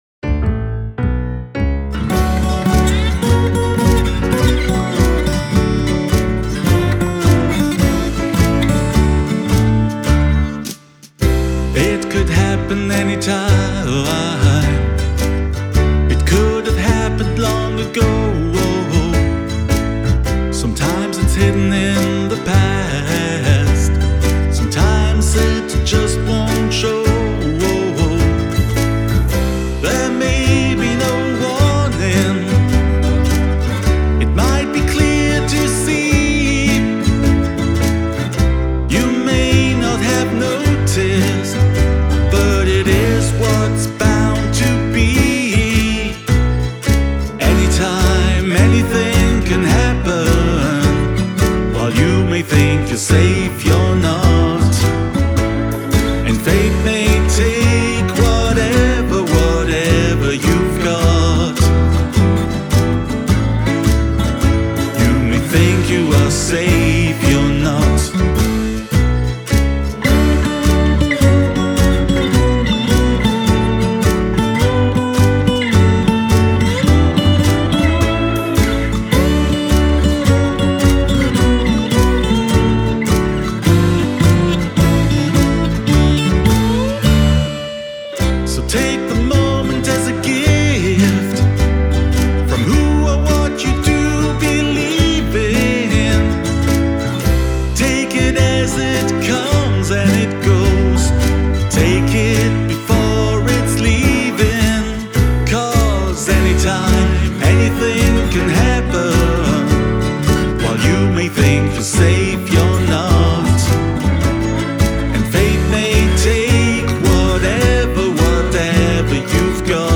Bediaz Black Gloss -kitaralla on hyvin raikas ääni pienellä keskialueen korostuksella.
Bediazin Weissenborn-kopio ehti myös jo mukaan yhteen biisidemoon: